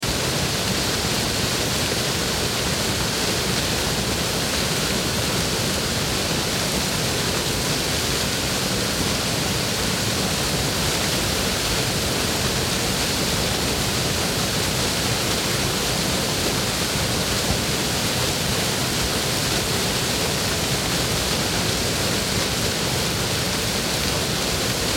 دانلود آهنگ جنگل 11 از افکت صوتی طبیعت و محیط
دانلود صدای جنگل 11 از ساعد نیوز با لینک مستقیم و کیفیت بالا
جلوه های صوتی